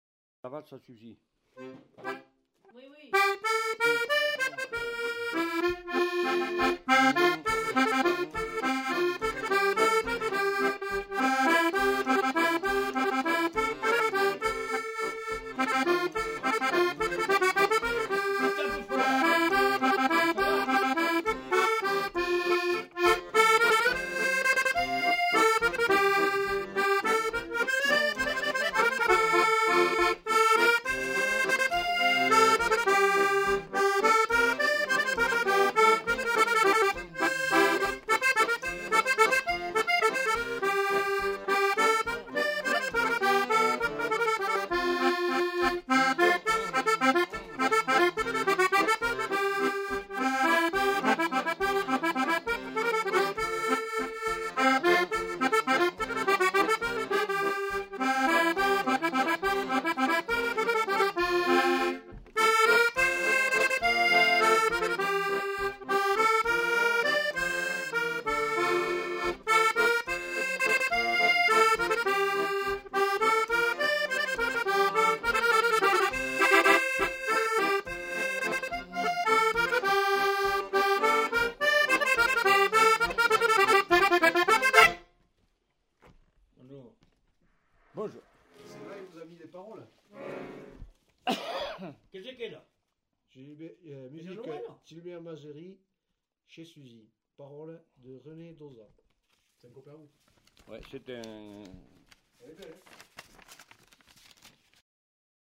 Aire culturelle : Quercy
Lieu : Labathude
Genre : morceau instrumental
Instrument de musique : accordéon chromatique
Danse : valse